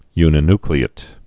(ynĭ-nklē-ĭt, -ny-)